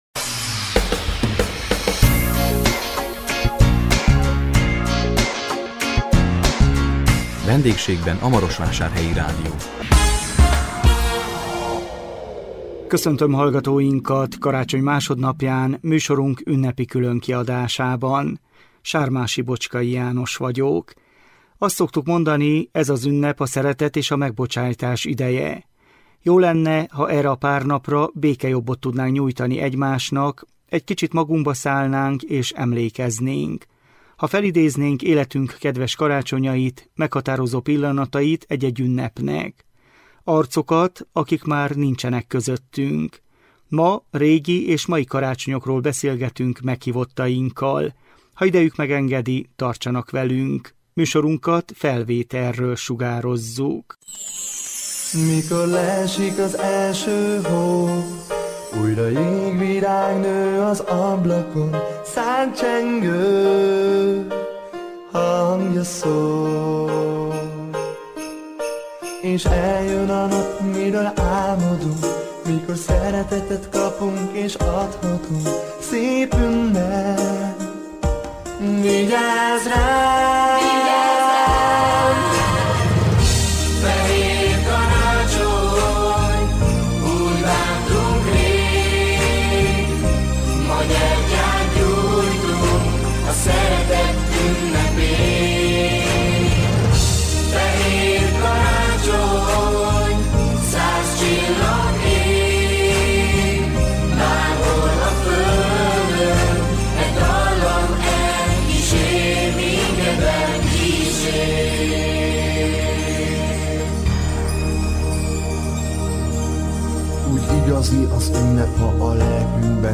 Régi és mai karácsonyokról beszélgettünk meghívottainkkal.